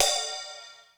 Ride.wav